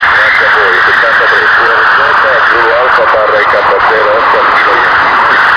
Hear His Signal in Rome!